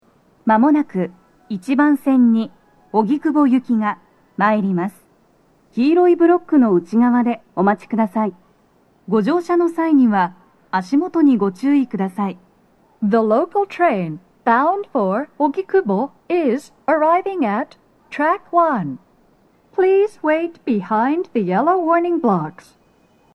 女声
接近放送2
TOA弦型での収録です。